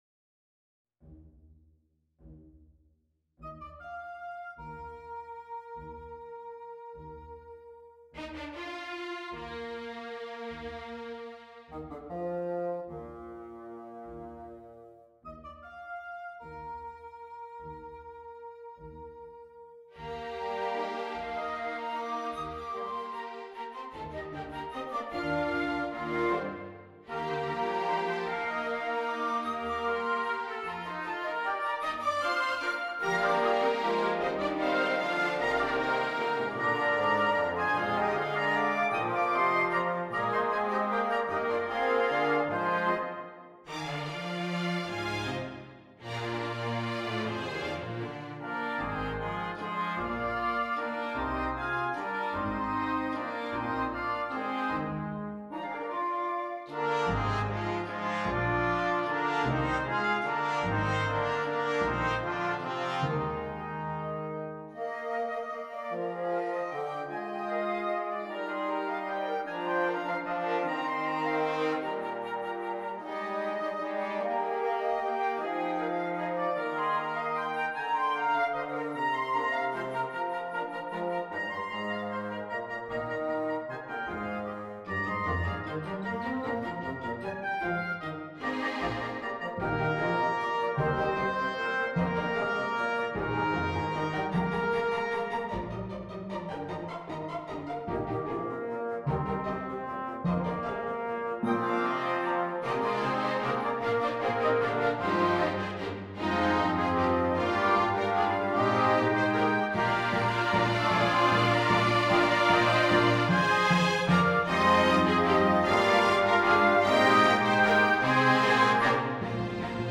Intermezzo - Orchestral and Large Ensemble - Young Composers Music Forum